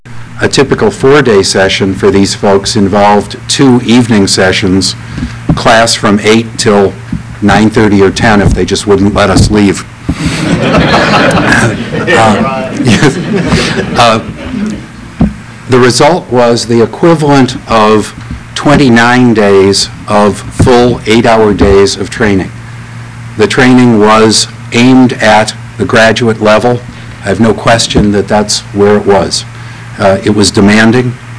June 3, 1999 graduation of first class